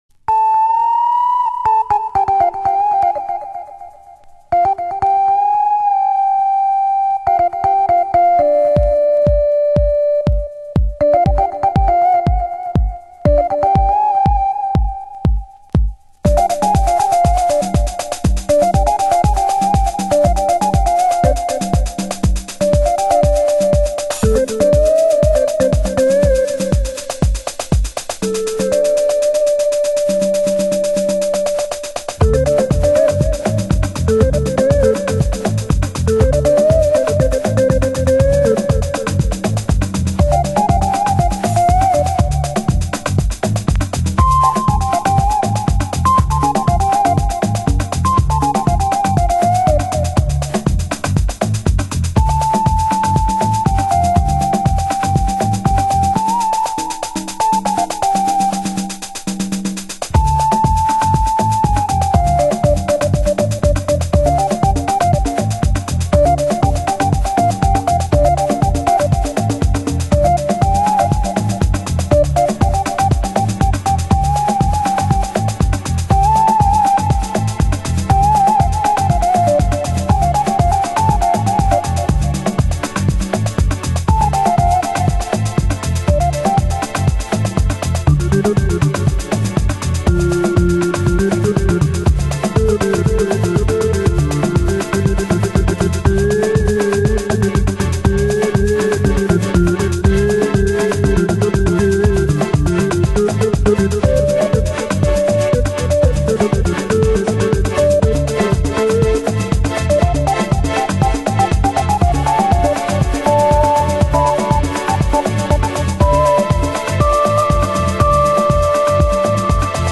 有/少しチリパチノイズ有